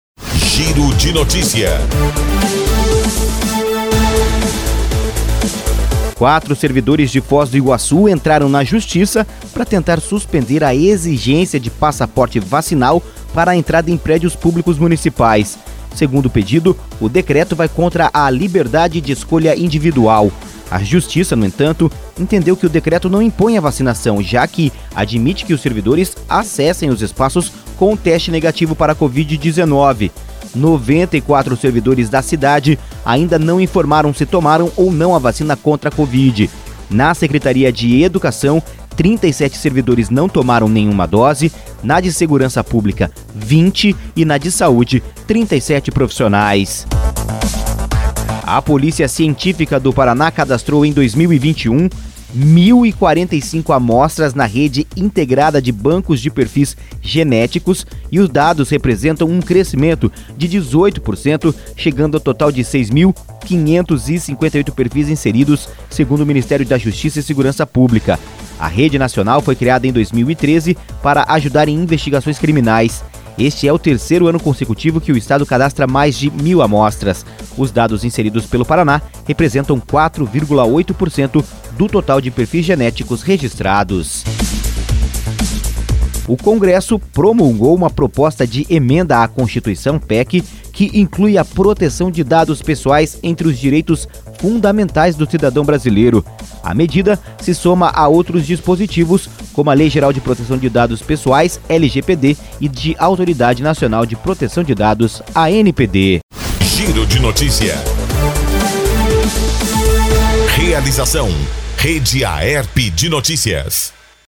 Giro de Notícias – Manhã